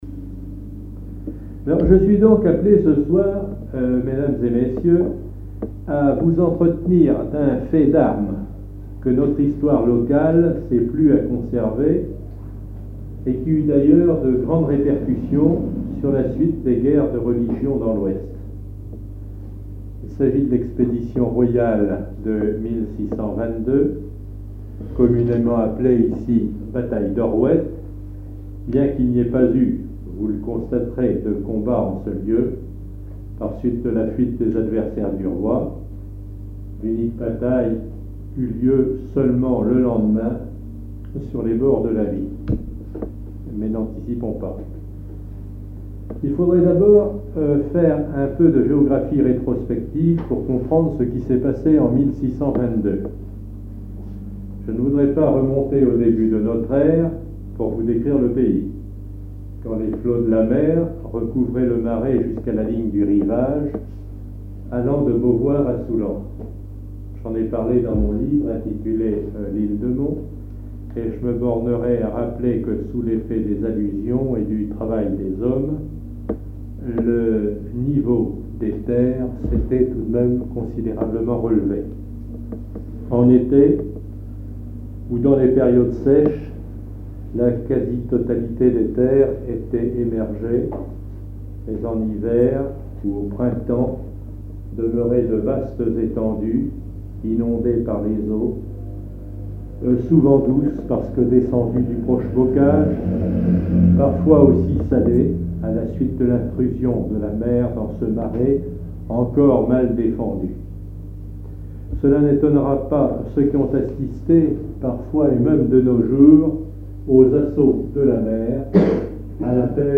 congrès, colloque, séminaire, conférence
Catégorie Témoignage